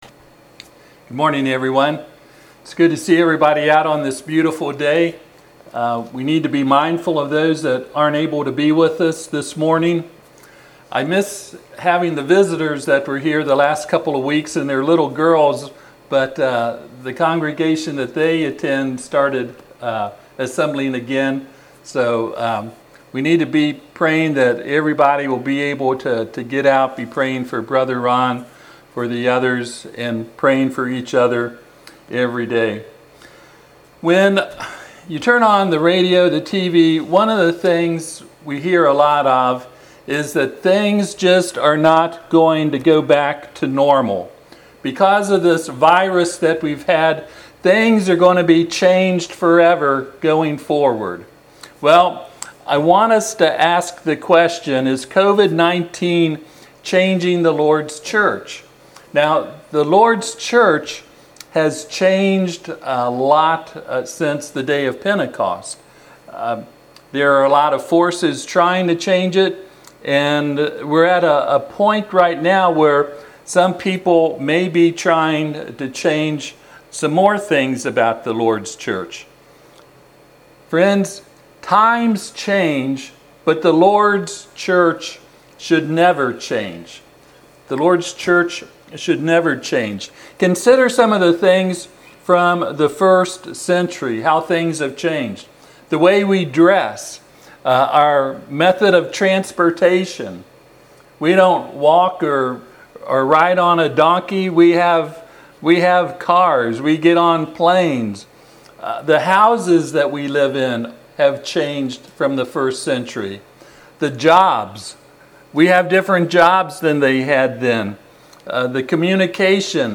Passage: 1 Corinthians 11:17-20 Service Type: Sunday AM « Evidence Of Authority.